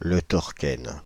Le Torquesne (French pronunciation: [lə tɔʁkɛn]
Fr-Le_Torquesne.ogg.mp3